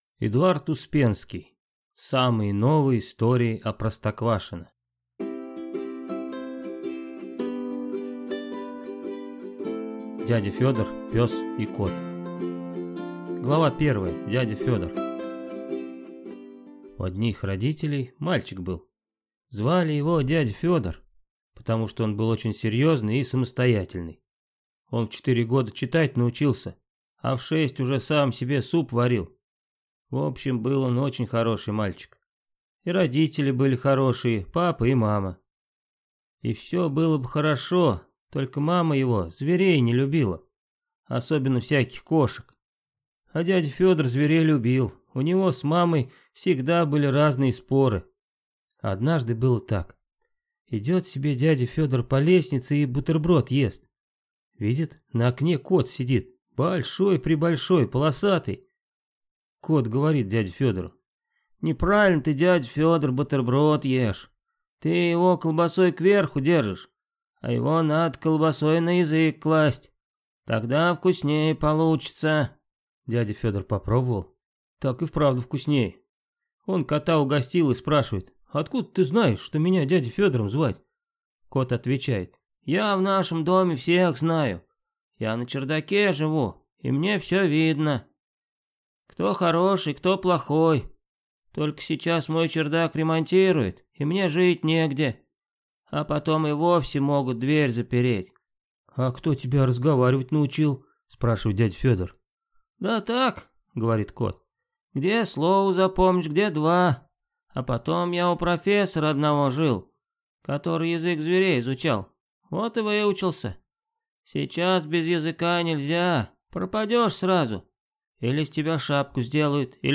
Аудиокнига Самые новые истории о Простоквашино | Библиотека аудиокниг
Прослушать и бесплатно скачать фрагмент аудиокниги